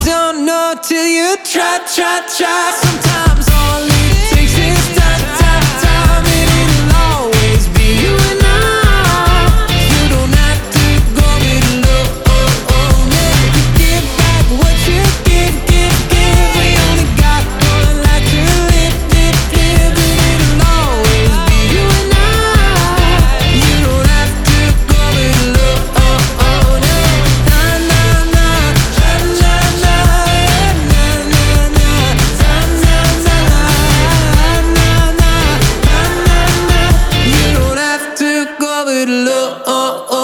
• Качество: 320, Stereo
поп
позитивные
красивый мужской голос
веселые
энергичные